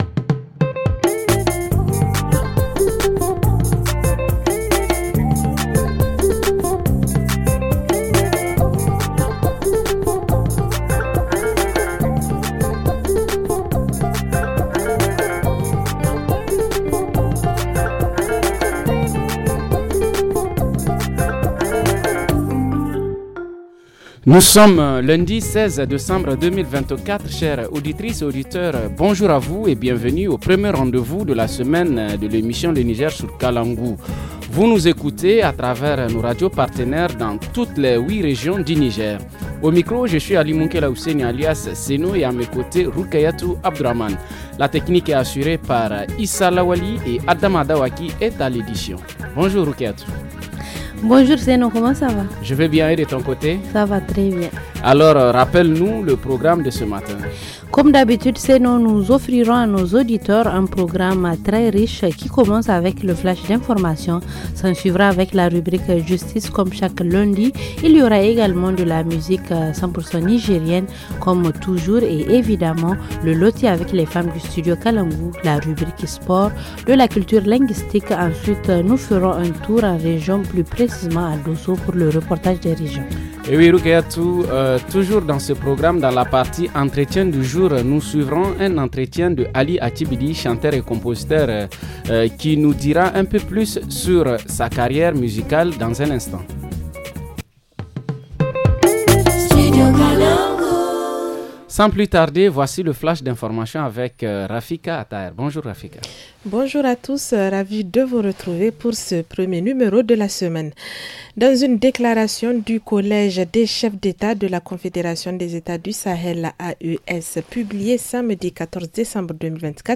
Dans la rubrique hebdomadaire, décryptage de l’article 73 du Code du travail. En reportage régional, focus sur les risques auxquels sont confrontés les petits commerces tenus par de jeunes filles à Malgorou.